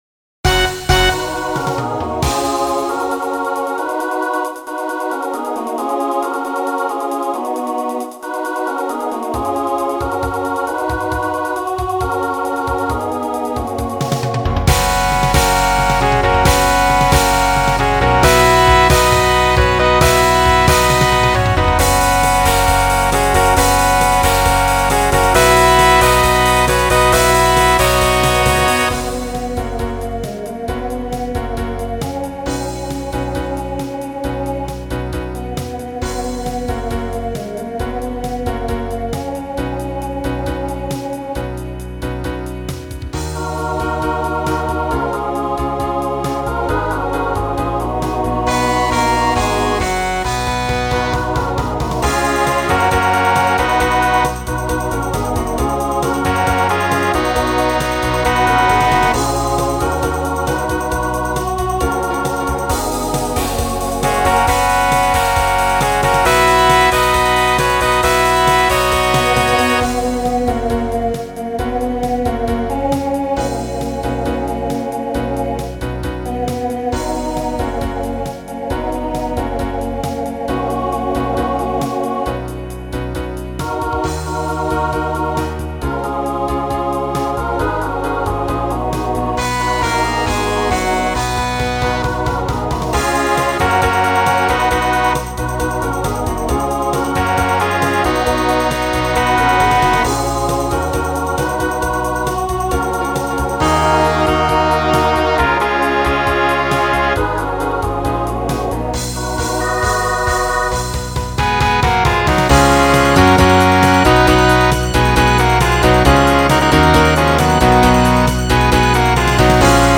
New SATB voicing for 2020